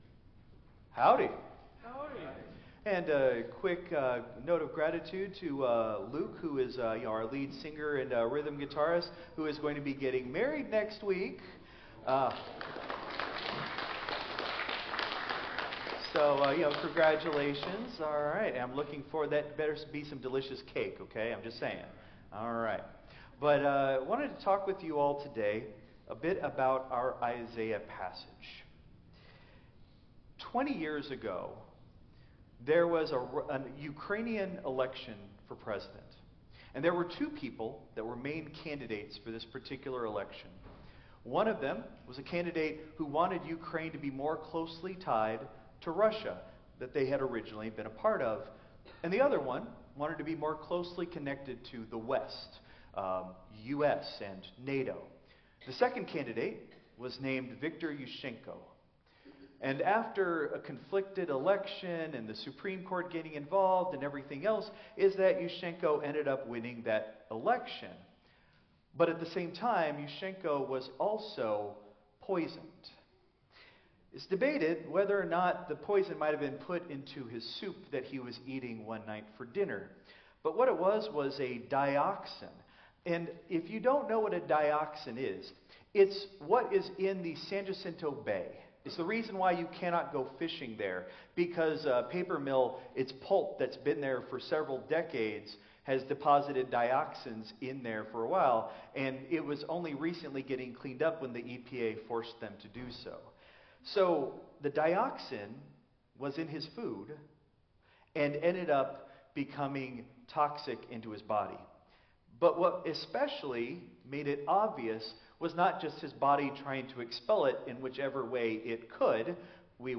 Christ Memorial Lutheran Church - Houston TX - CMLC 2025-02-09 Sermon (Contemporary)